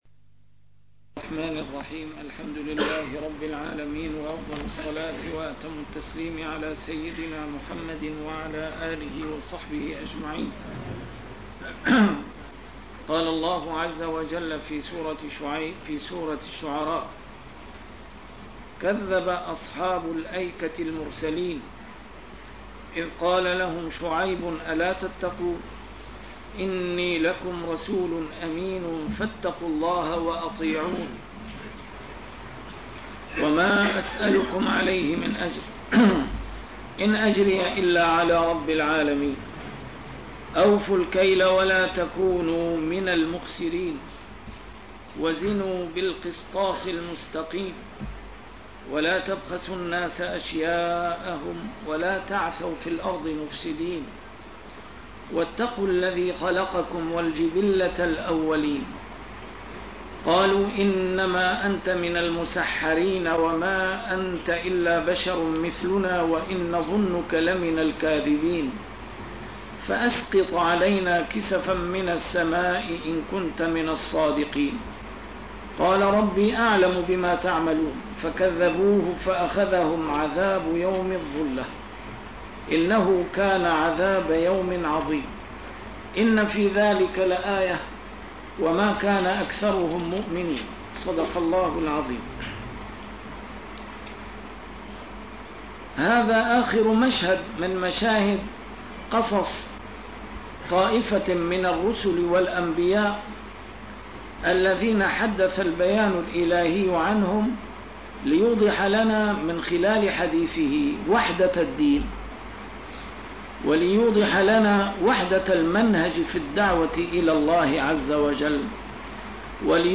A MARTYR SCHOLAR: IMAM MUHAMMAD SAEED RAMADAN AL-BOUTI - الدروس العلمية - تفسير القرآن الكريم - تسجيل قديم - الدرس 236: الشعراء 176-184